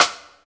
SNR THIN S0H.wav